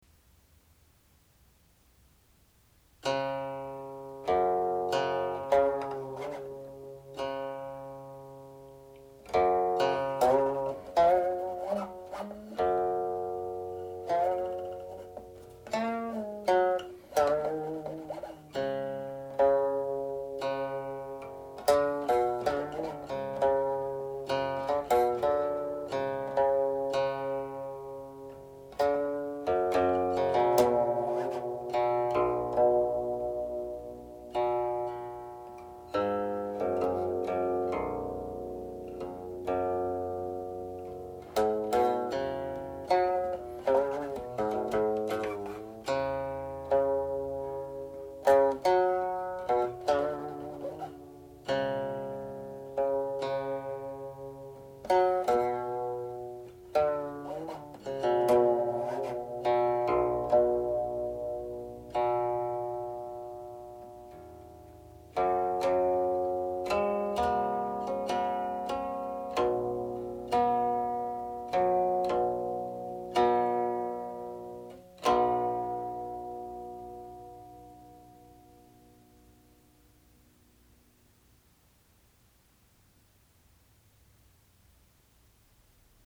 However, it shares with them similar modal characteristics, in particular the inclusion of both standard mi with flatted mi.